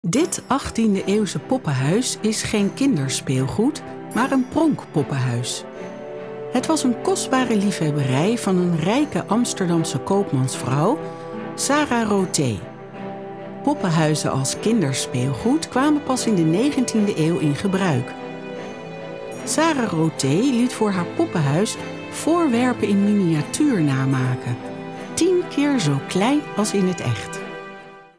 Travieso, Versátil, Seguro, Amable, Cálida
Explicador